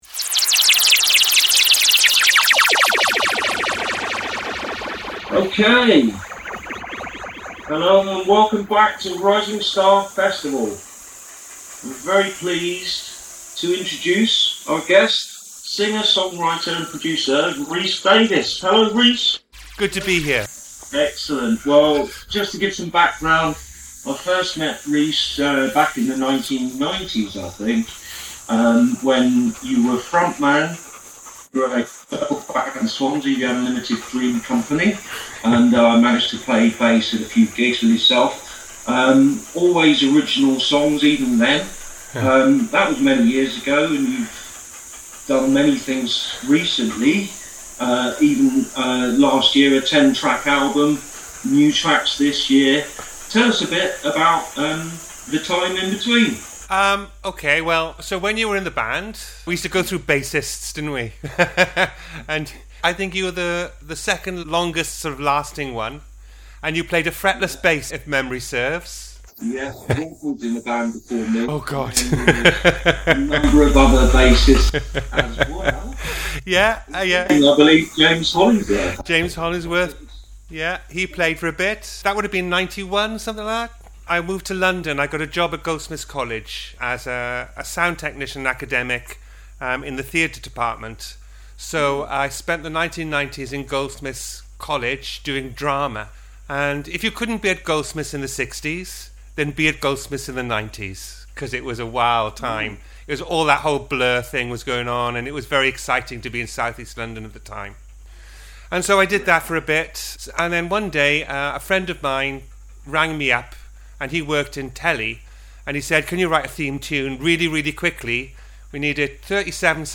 Resonant Radio Interview (Um & Ah Edit).mp3